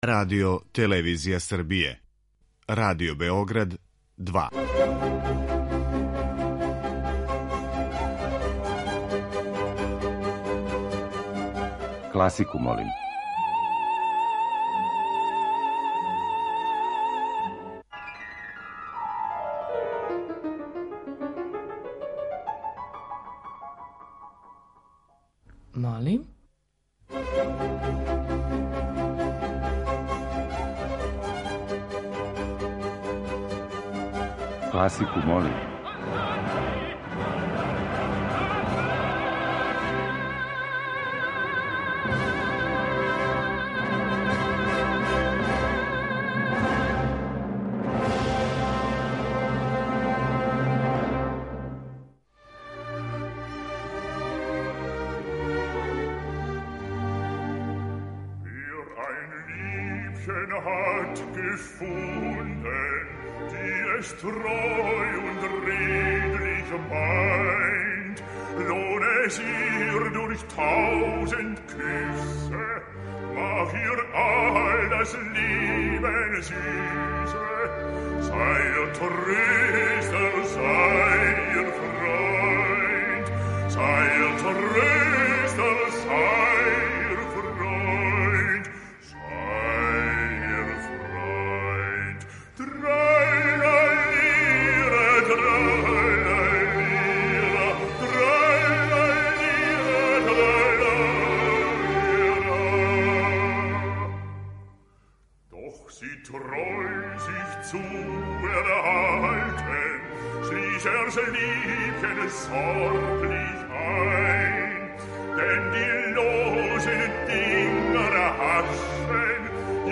Избор за топ-листу класичне музике Радио Београда 2
klasika.mp3